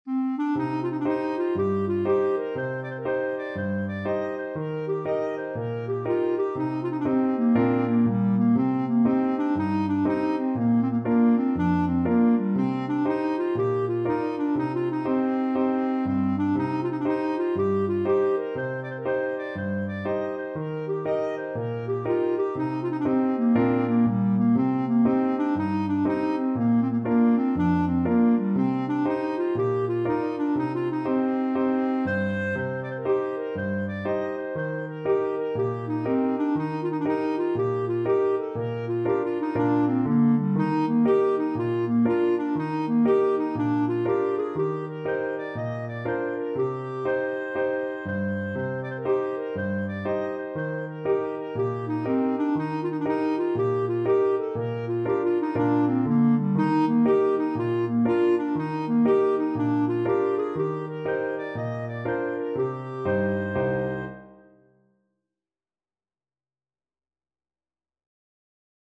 Clarinet
4/4 (View more 4/4 Music)
G4-Eb6
Bb major (Sounding Pitch) C major (Clarinet in Bb) (View more Bb major Music for Clarinet )
Allegro (View more music marked Allegro)
Traditional (View more Traditional Clarinet Music)
galway_bay_CL.mp3